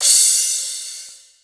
43_05_cymbal.wav